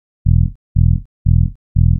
TSNRG2 Off Bass 019.wav